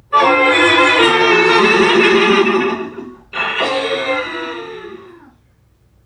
NPC_Creatures_Vocalisations_Robothead [51].wav